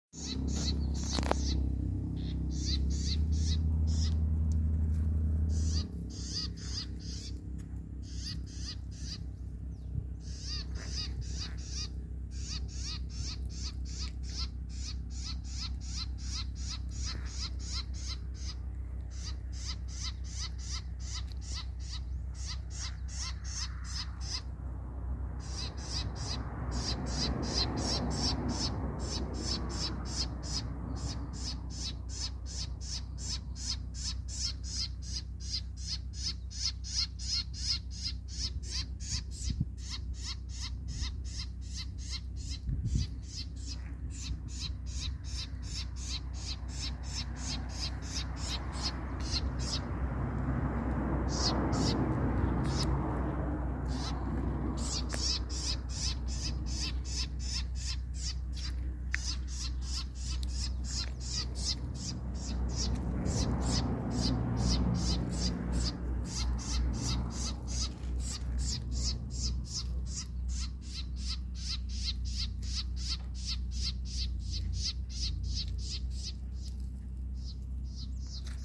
Ratona Común (Troglodytes musculus)
Nombre en inglés: Southern House Wren
Localidad o área protegida: Santa María
Condición: Silvestre
Certeza: Filmada, Vocalización Grabada